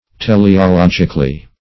[PJC] -- Te`le*o*log"ic*al*ly , adv.
teleologically.mp3